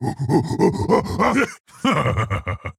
(grunts and laughs).Overwatch Logo.png存在于守望先锋1代
(咕哝并大笑)